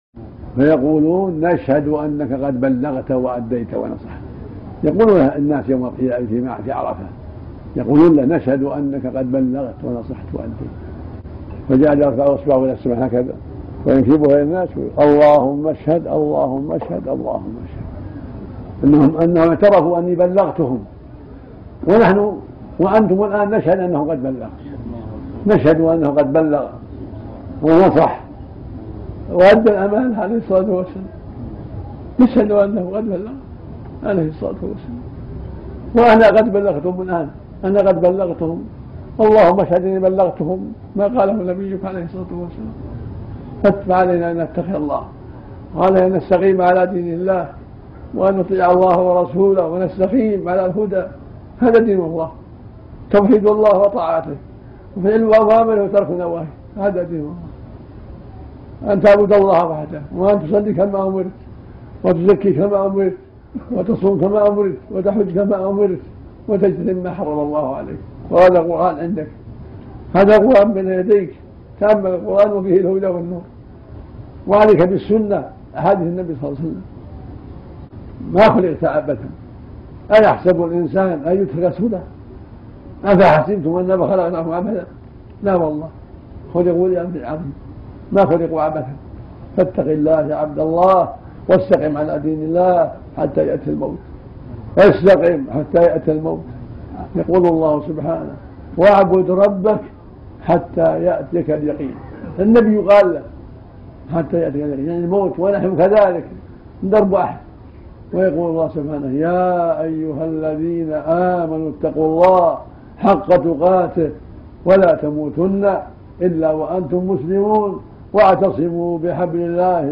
[Crying at Minaa during Hajj in 1996]
crying-at-minaa-during-hajj-in-1416.mp3